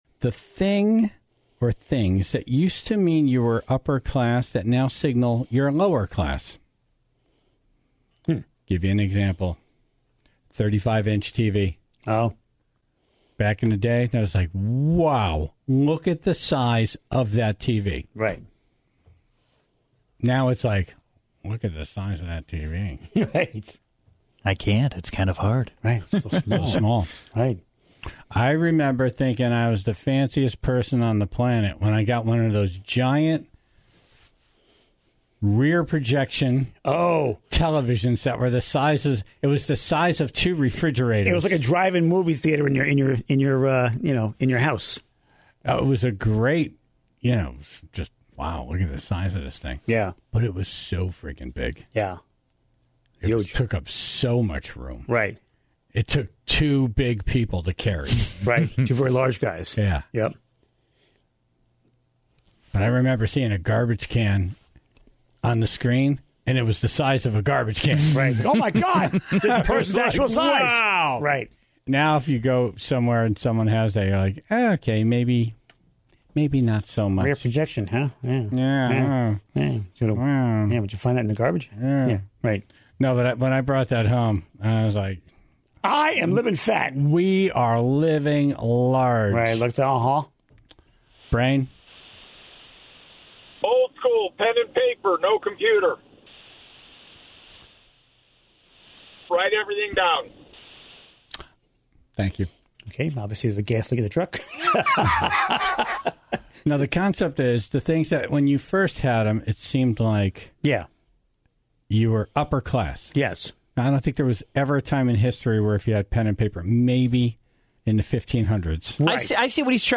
took loads of calls from the Tribe on the items they used to think meant you were living large, but now seem really simple.